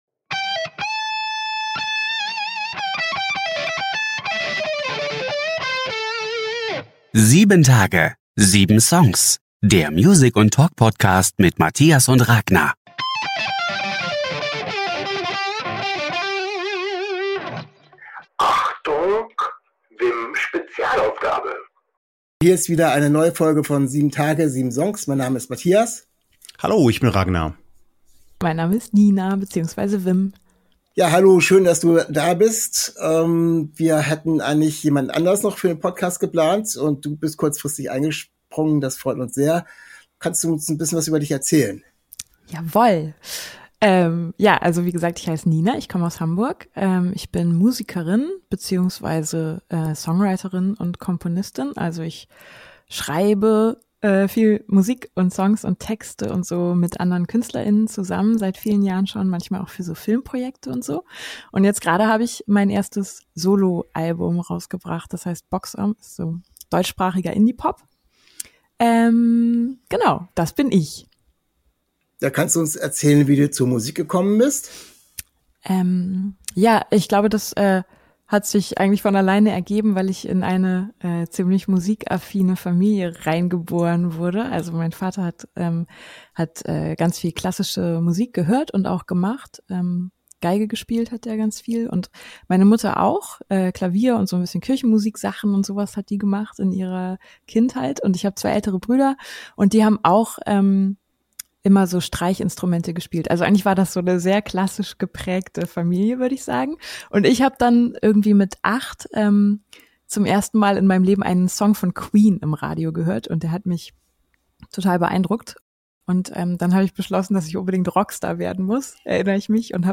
Music + Talk Podcast ~ 7 Tage 7 Songs Podcast